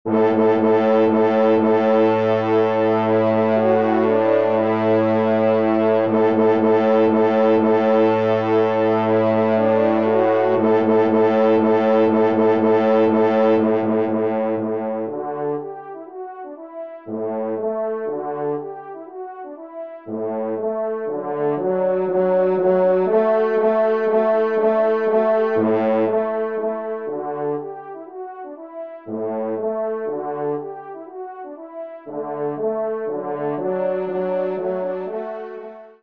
Genre : Divertissement pour Trompes ou Cors
Pupitre 3° Cor